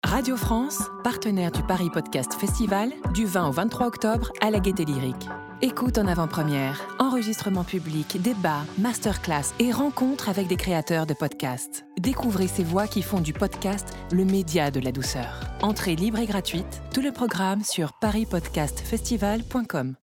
30 - 50 ans - Mezzo-soprano